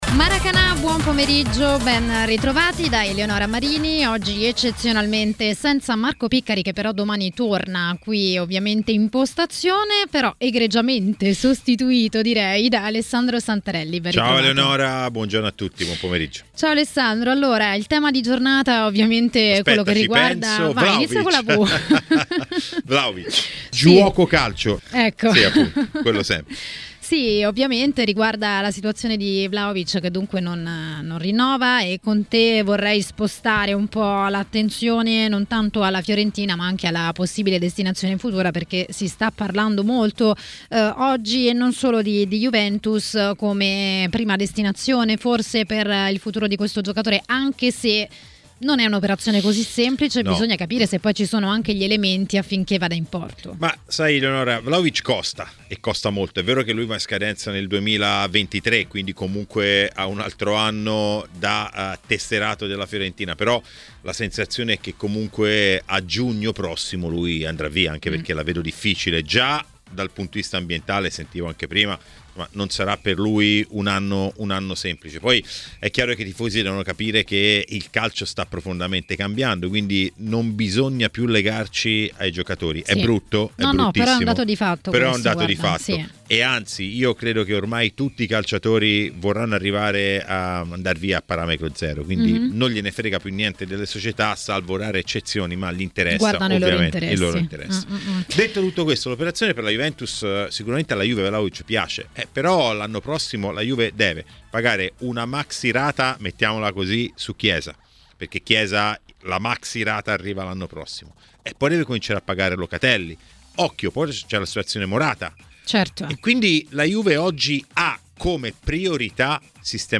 A Maracanà, trasmissione di TMW Radio, è intervenuto mister Gianni Di Marzio per parlare dei temi del giorno.